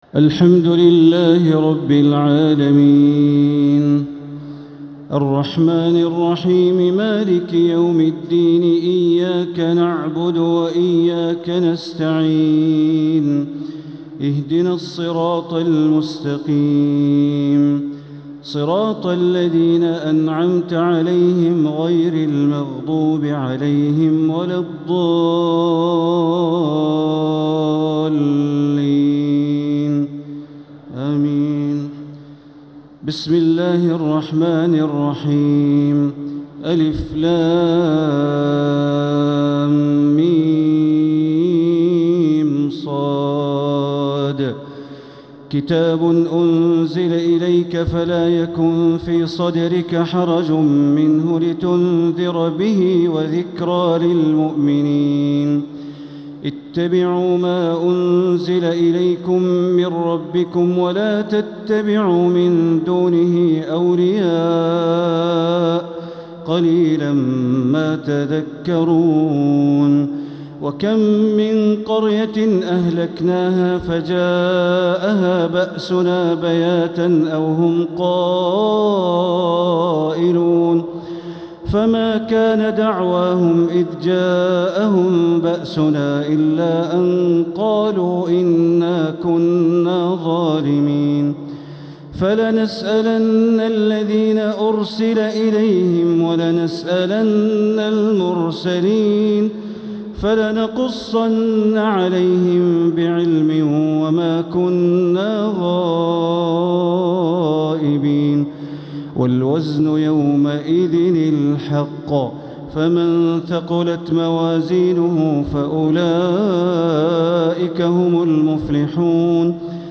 تراويح ليلة 11 رمضان 1447هـ من سورة الأعراف (1-79) | Taraweeh 11th night Ramadan 1447H Surah Al-Araf > تراويح الحرم المكي عام 1447 🕋 > التراويح - تلاوات الحرمين